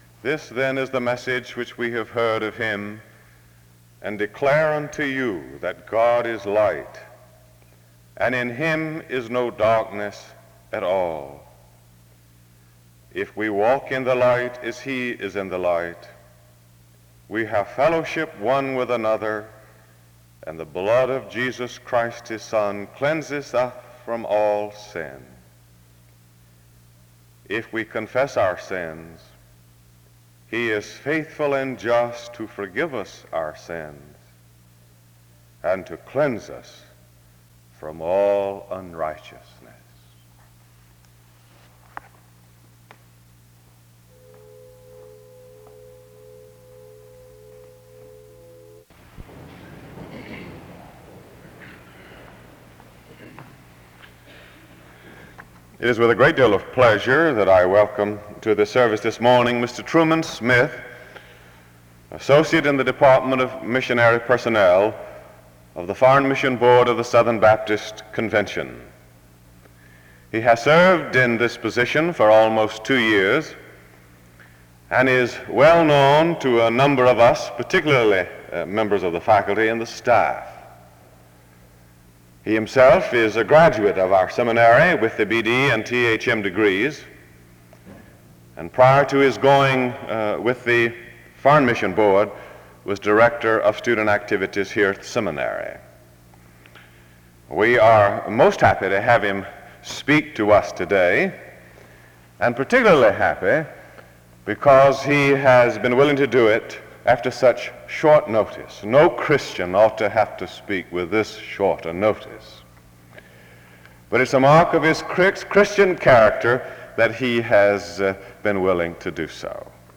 The service opens with a reading of a passage of scripture from 0:00-0:42. An introduction is made from 0:55-2:02.